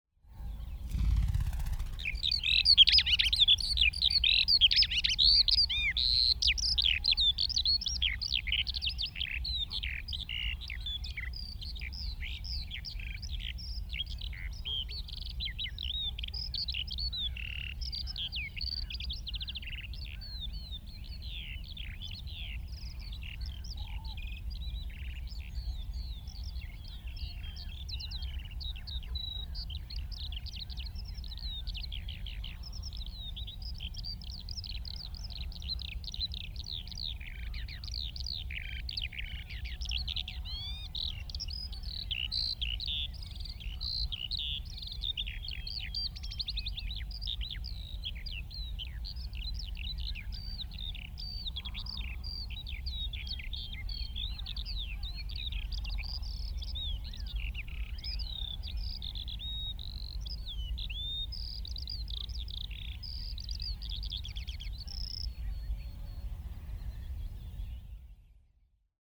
Het geluid van een Veldleeuwerik
• De zang van de veldleeuwerik is uniek door zijn lange, melodieuze liederen en de imitaties van andere vogelsoorten.
Deze vogel zingt lange, melodieuze liederen die ver kunnen dragen over de velden.
Deze vogels voegen geluiden van andere soorten toe aan hun lied. Hun zang kan heel lang doorgaan zonder te stoppen.
Luisteraars genieten van een breed scala aan tonen en melodieën.
Het klinkt als een eindeloze tierelier.